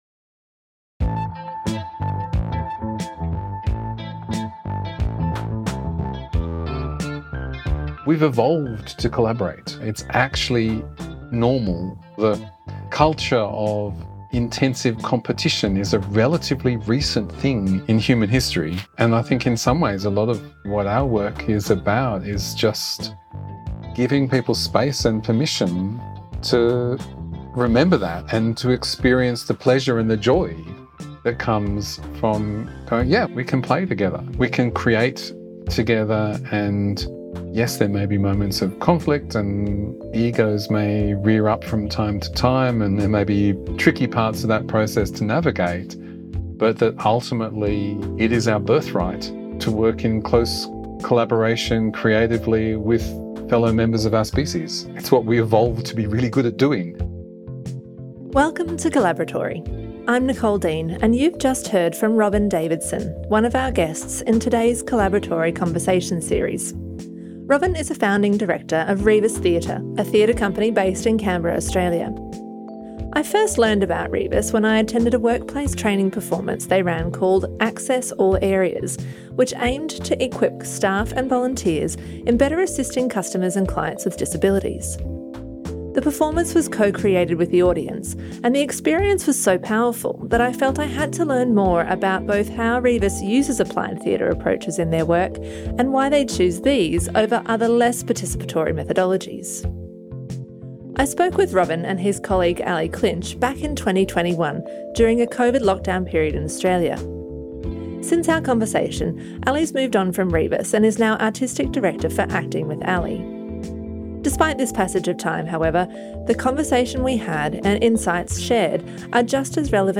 How can we use theatre to create spaces for authentic dialogue, empathy and seeing from new perspectives? Spaces where people can play together, create together and take action on issues of personal and social importance? Find out in this episode of Collaboratory Conversations, where we bring you an edited interview with Canberra-based theatre company, Rebus Theatre.